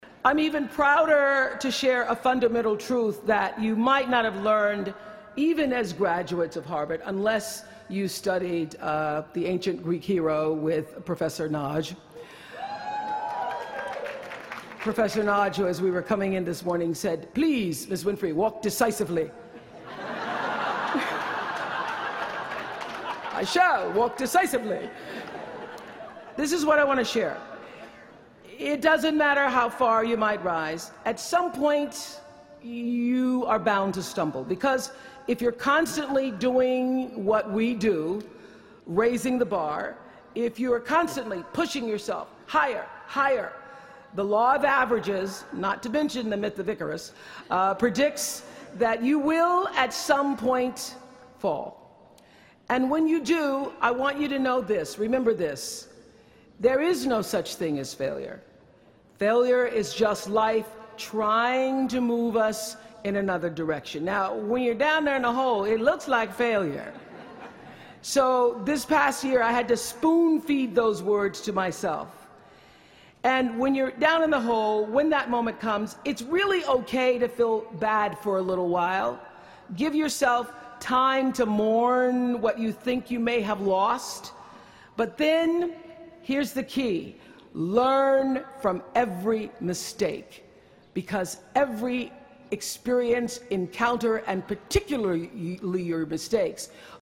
公众人物毕业演讲第354期:奥普拉2013在哈佛大学(6) 听力文件下载—在线英语听力室